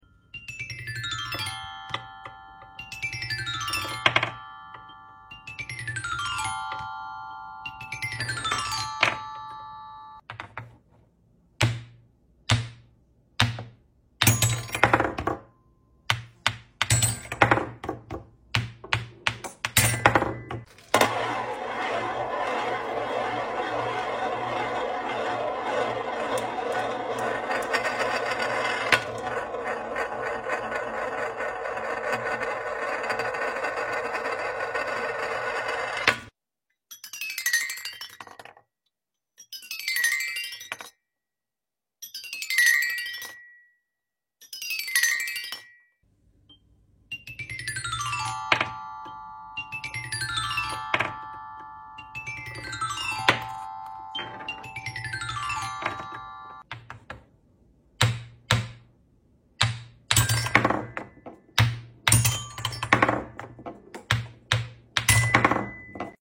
Color Balls Melody | Xylophone sound effects free download